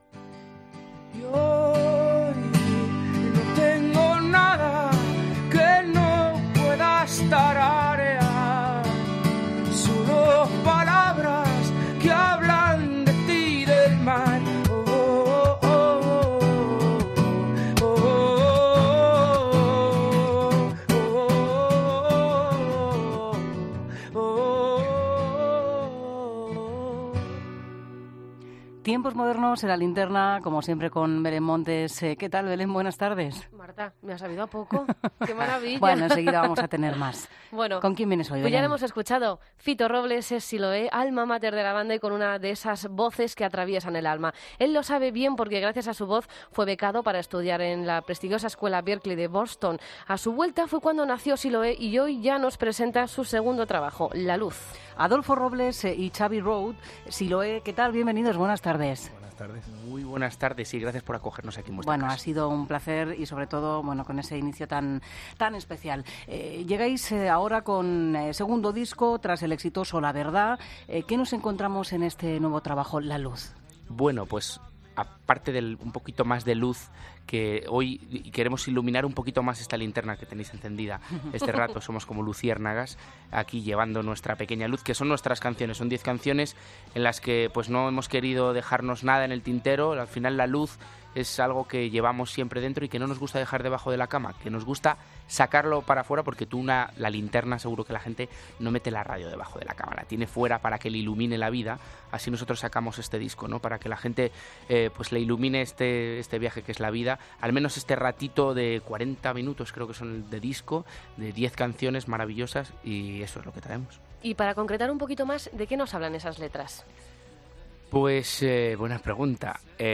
Entrevista a Siloé en La Linterna